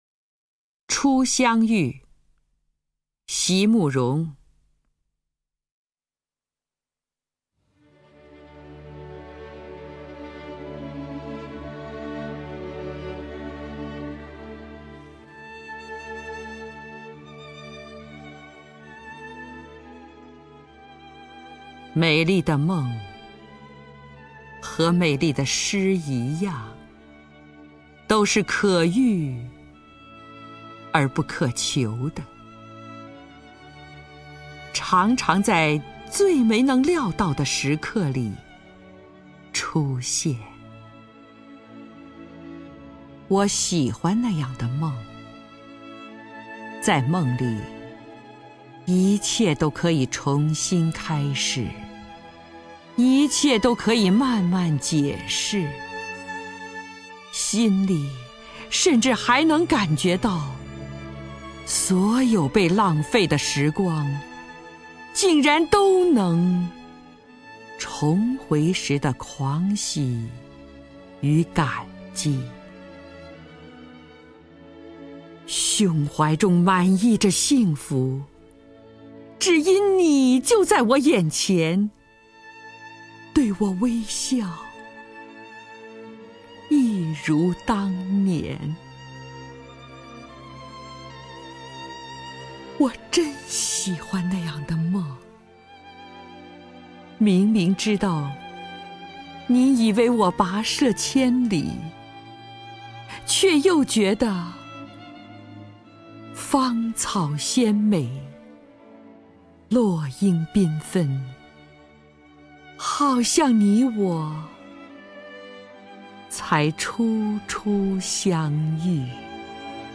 首页 视听 名家朗诵欣赏 张筠英
张筠英朗诵：《初相遇》(席慕容)　/ 席慕容
ChuXiangYu_XiMuRong(ZhangJunYing).mp3